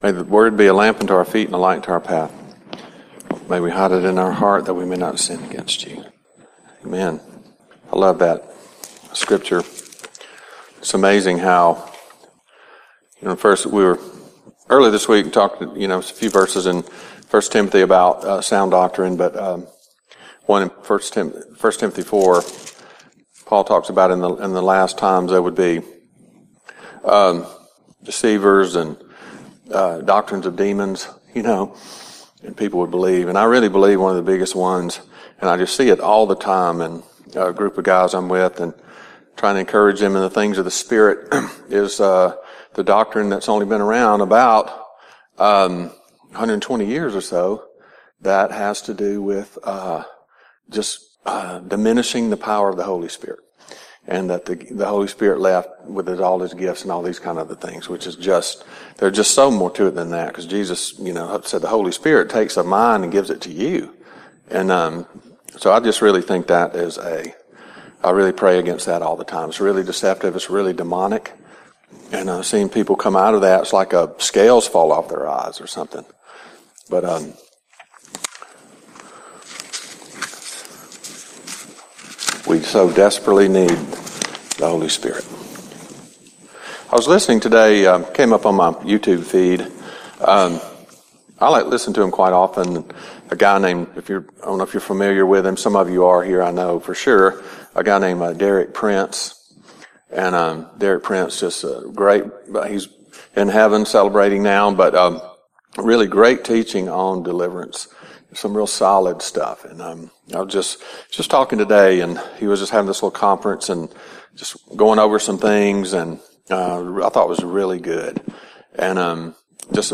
Service Type: CTK Noon Service